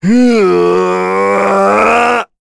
Dakaris-Vox_Casting4.wav